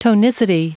Prononciation du mot tonicity en anglais (fichier audio)
Prononciation du mot : tonicity